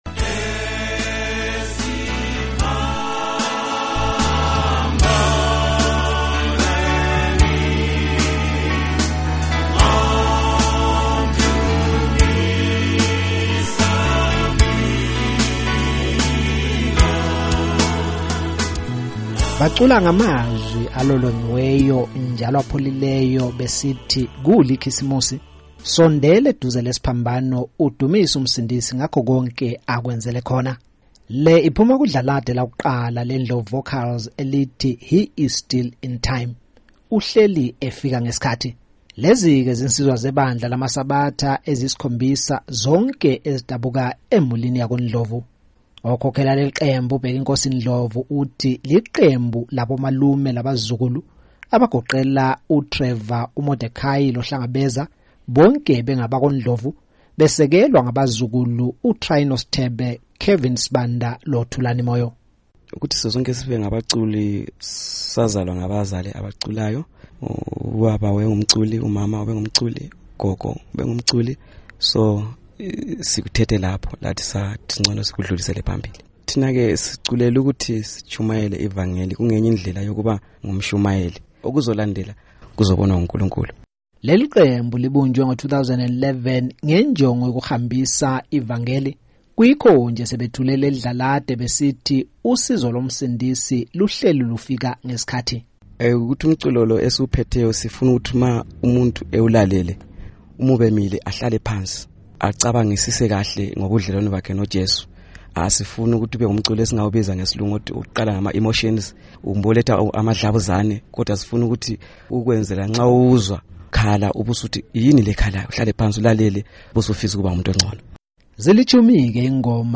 Bacula ngamazwi alolongiweyo, njalo apholileyo besithi kule iKhisimusi sondela eduze lesiphambano udumise uMsindisi ngakho konke akwenzele khona.